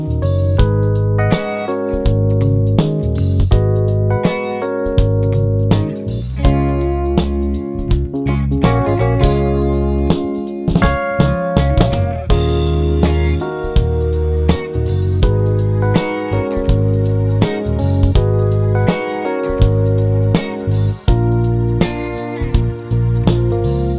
no Backing Vocals Soul / Motown 4:10 Buy £1.50